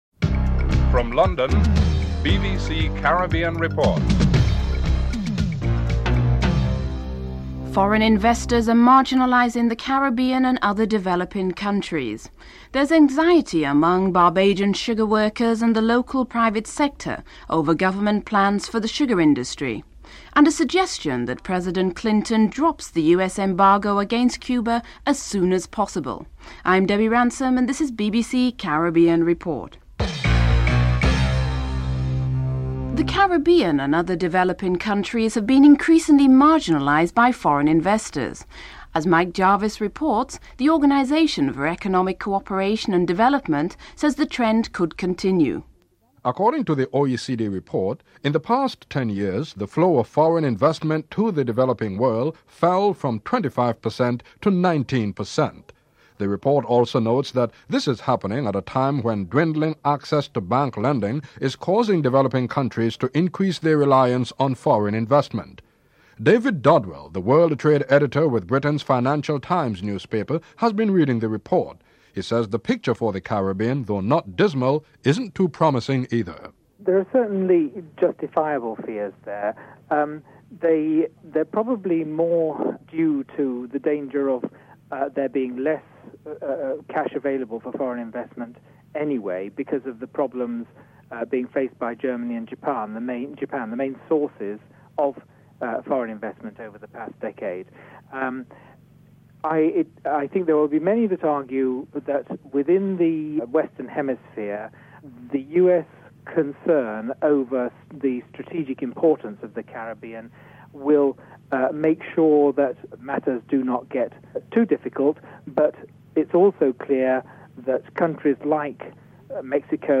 Headlines